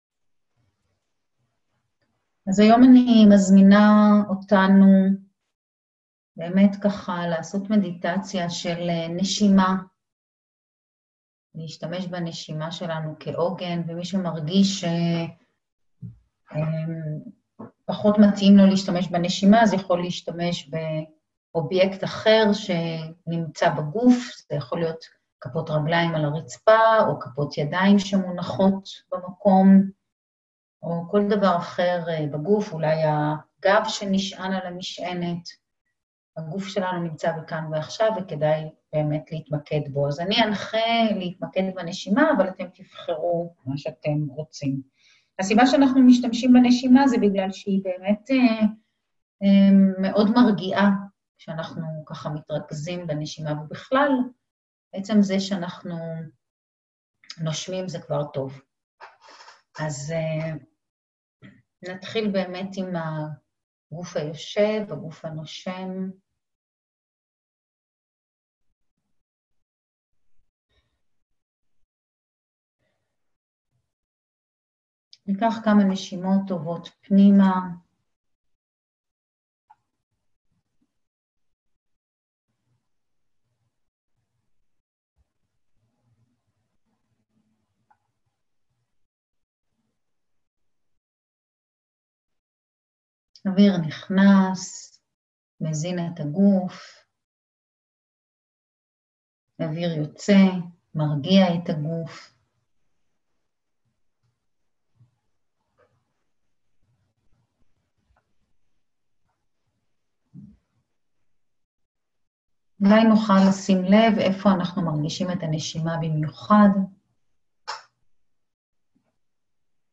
תכנים מחבקים וירטואלית: הקלטות של מדיטציות/הדרכות/הסברים בנושא קשיבות (Mindfulness) וחמלה עצמית (MSC)
מדיטציה-לשקט-נפשי.mp3